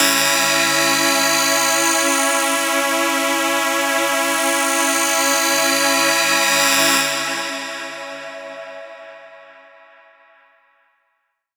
VEC3 FX Athmosphere 01.wav